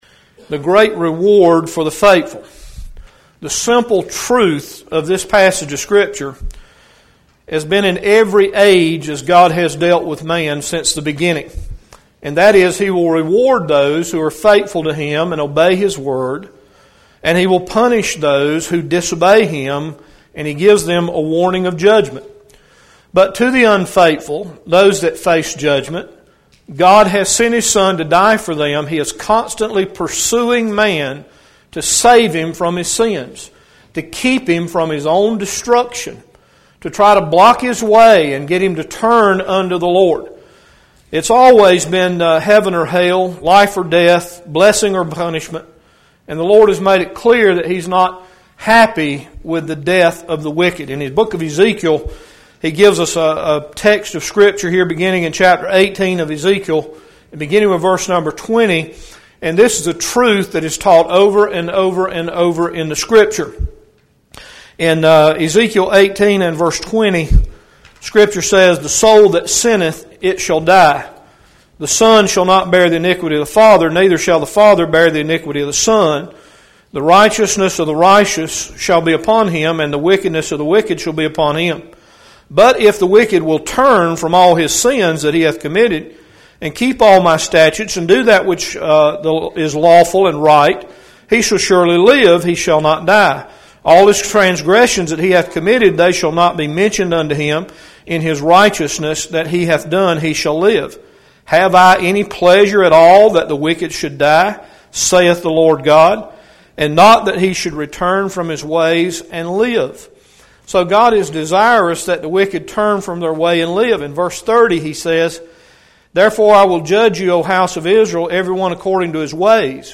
9-9-12-AM-message.mp3